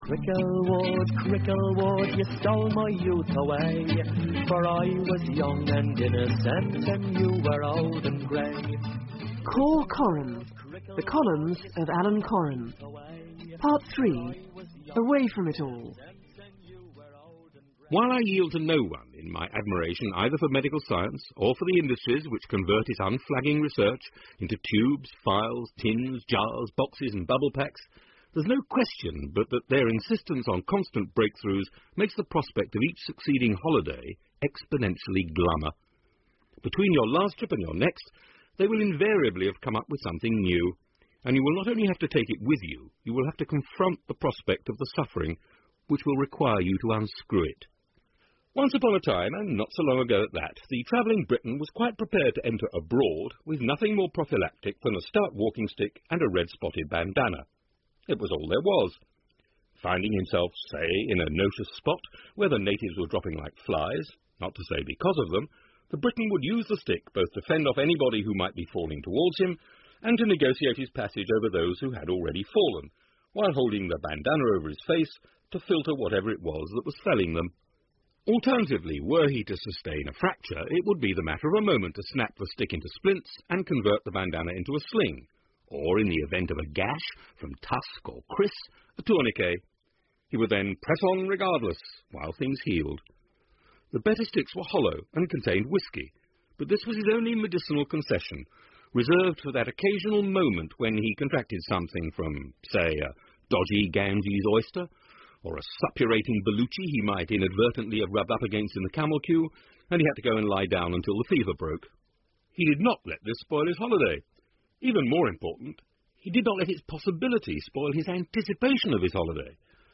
Alan Coren reads short essays from his books